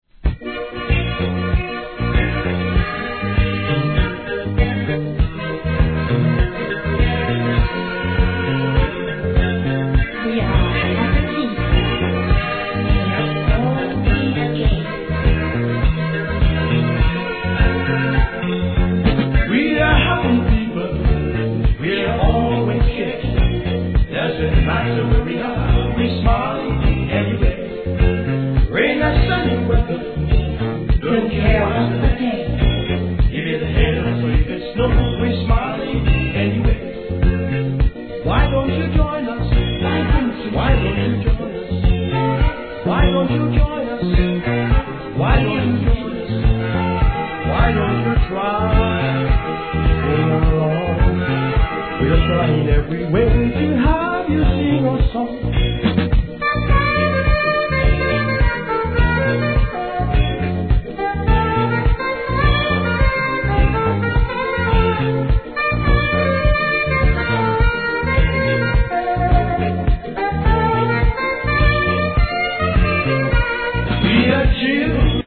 REGGAE
程よい四つ打ちリズムにSAXホーンが心地よく絡み、タイトル通り幸せな気持にさせてくれます!!